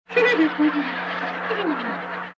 PLAY risa quico
risa-quico.mp3